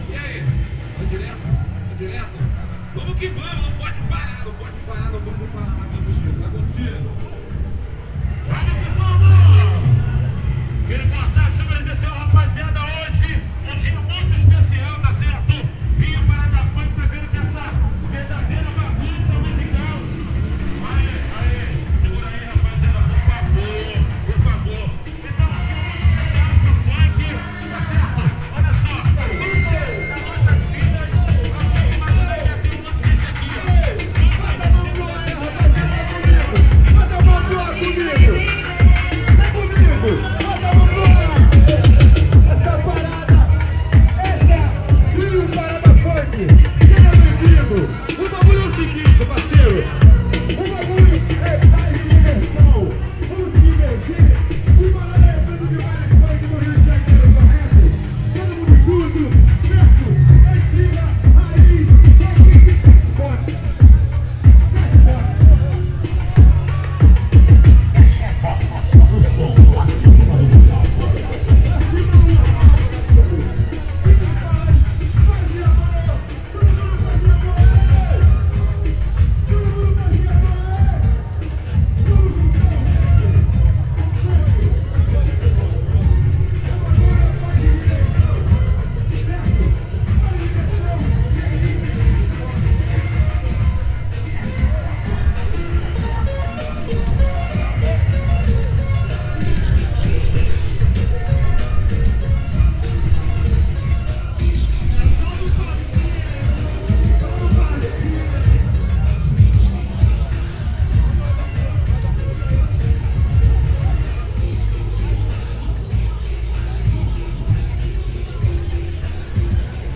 Field Recording Rio Parada Funk (recorded on ZOOM H4n)
This Sunday, however, muffled but loud bass reverberated from blocks away.
Ten sound systems with walls of between forty and one hundred stacked speakers–and one made of car sound systems– rumbled through funk’s history for over eight hours.
I walked through the dancing crowd past eight of the ten equipes which  lined the street, their little stages between their two big walls of sound.
field-recording-rio-parada-funk.m4a